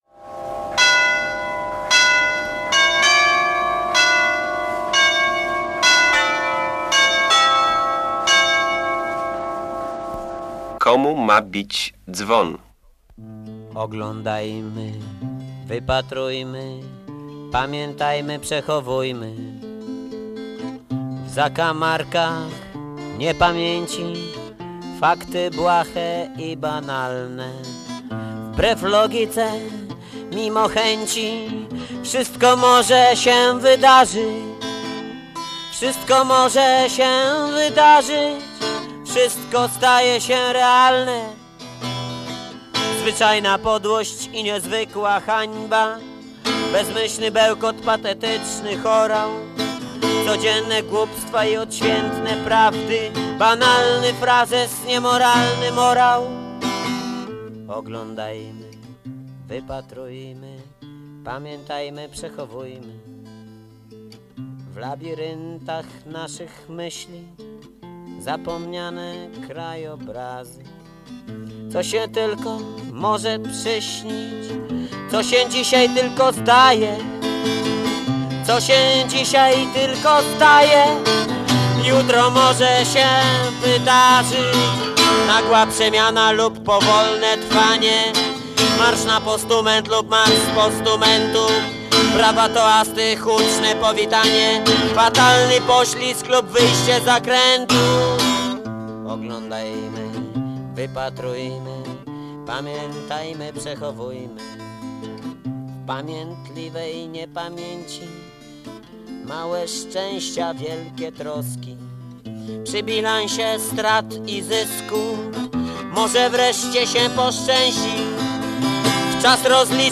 Audycja historiozoficzna